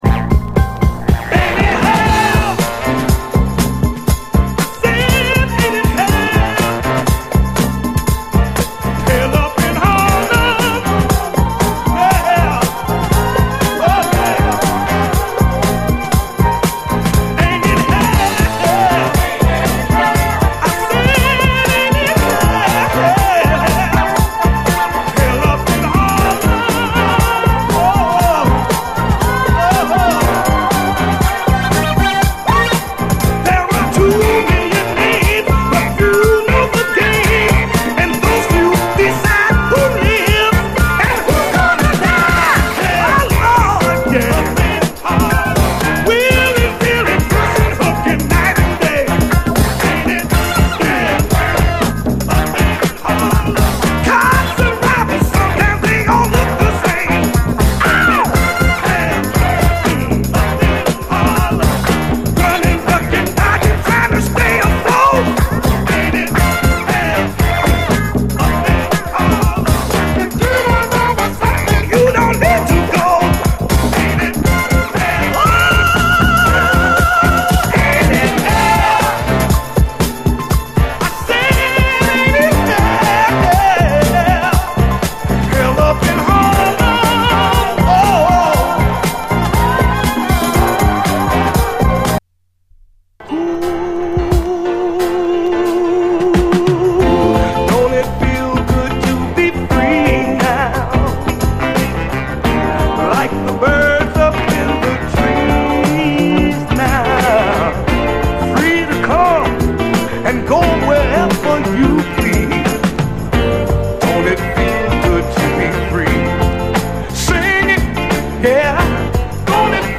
SOUL, 70's～ SOUL, 7INCH
優しいニューソウル・チューン